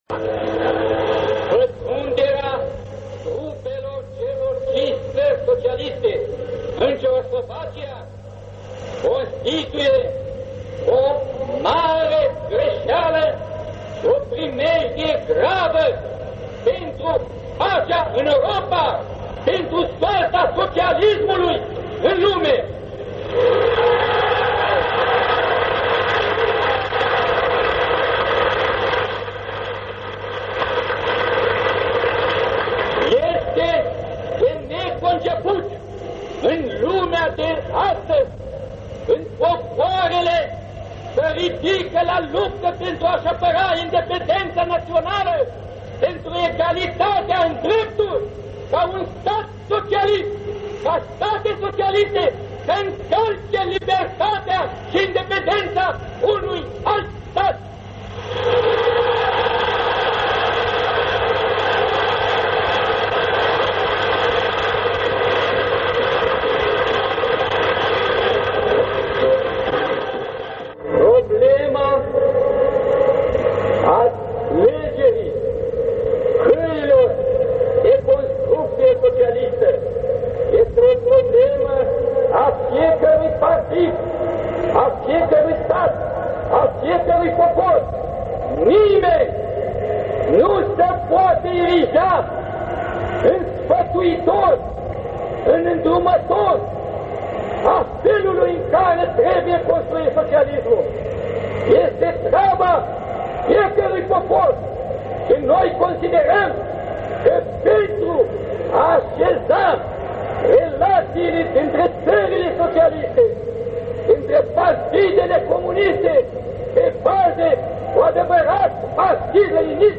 Discursul lui Nicolae Ceausescu din 22 august 1968 cu privire la invadarea Cehoslovaciei.
Inregistrare pe banda de magnetofon.